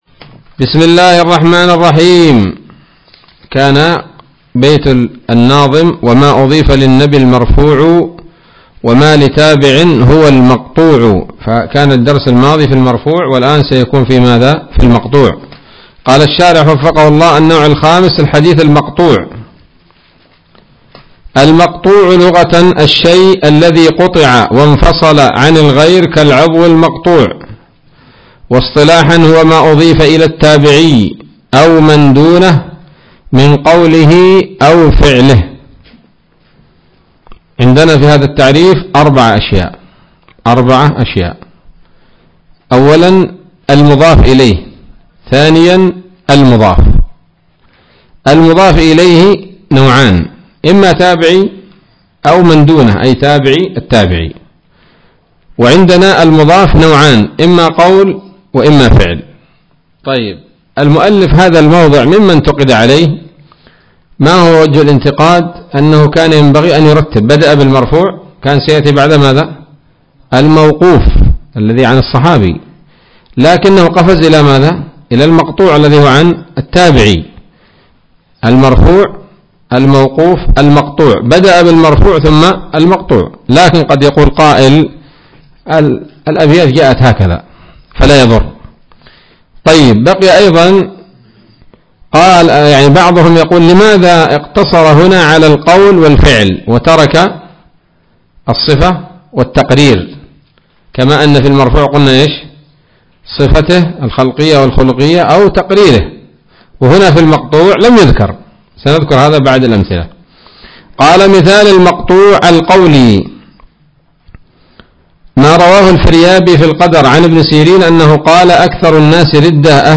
الدرس الحادي عشر من الفتوحات القيومية في شرح البيقونية [1444هـ]